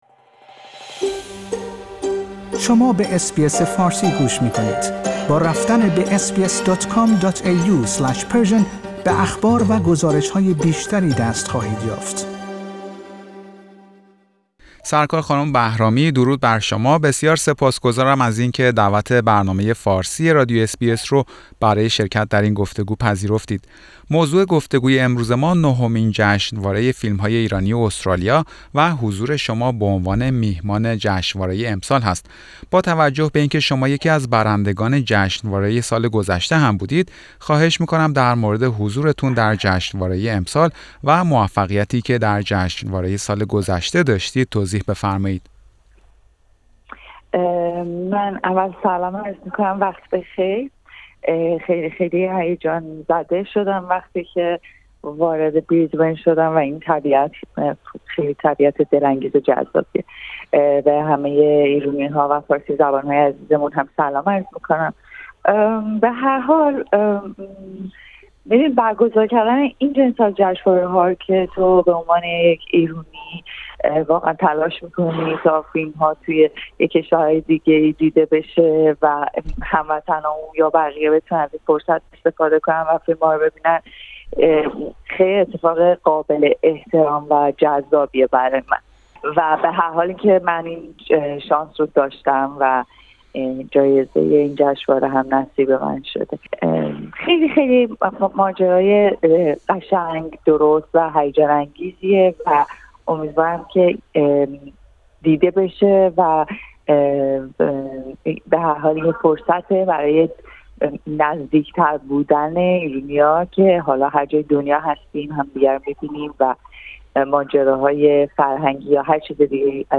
گفتگو با سارا بهرامی، میهمان ویژه نهمین جشنواره فیلم های ایرانی استرالیا